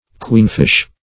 Queenfish \Queen"fish`\, n. (Zool.)